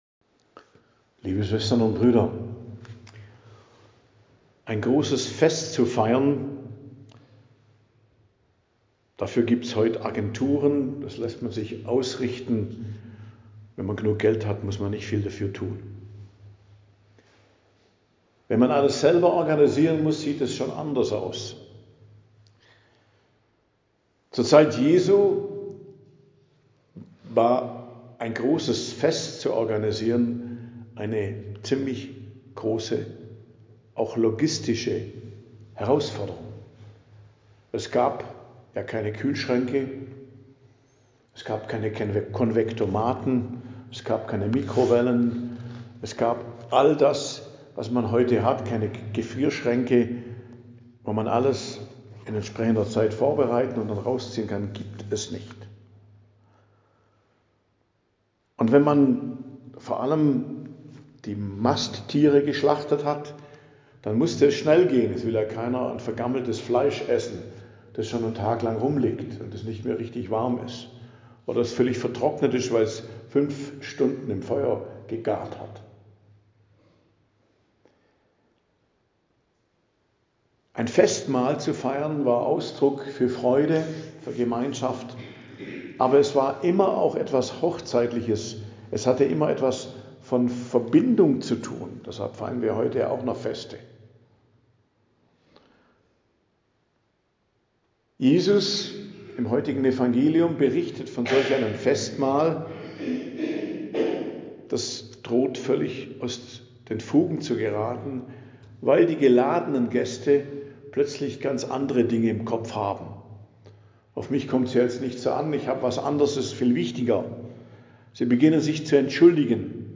Predigt am Dienstag der 31. Woche i.J., 4.11.2025